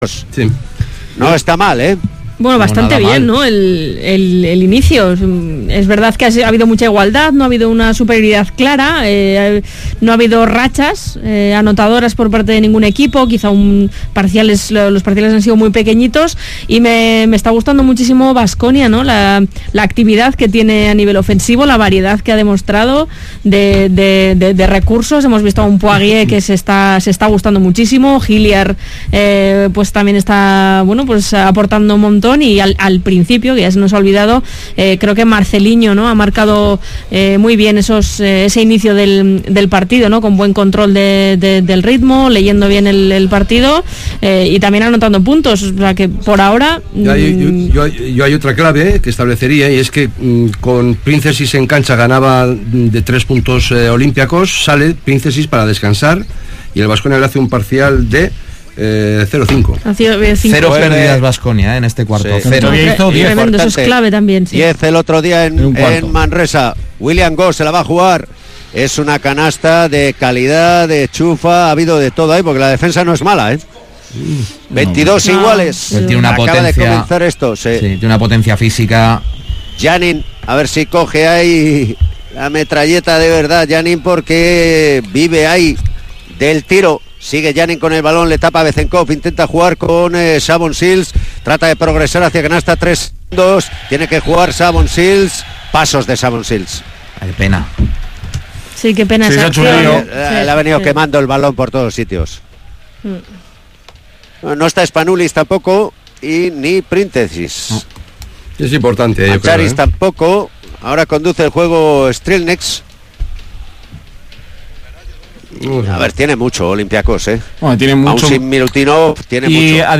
Olympiacos-baskonia jornada 17 euroleague 2018-19 retransmisión Radio Vitoria